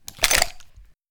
pistol Store.wav